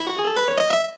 piano_scale_2.ogg